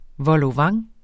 Udtale [ vʌloˈvɑŋ ]